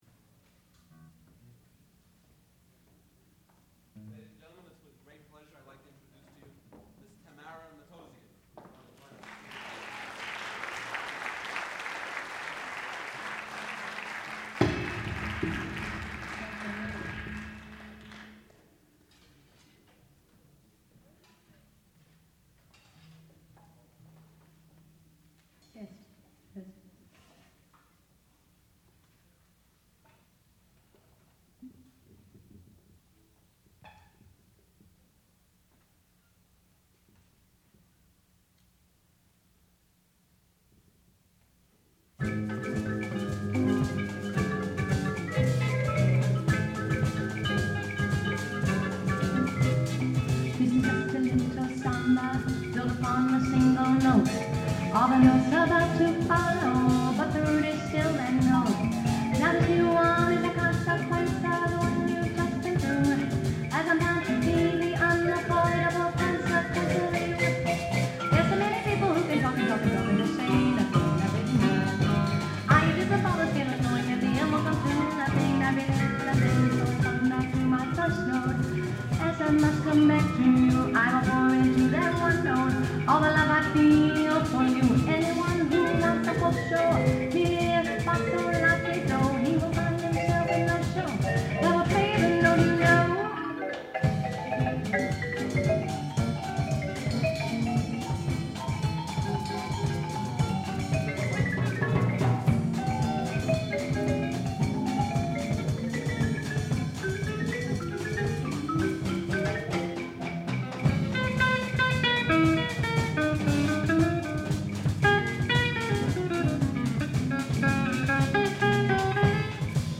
sound recording-musical
classical music
vocals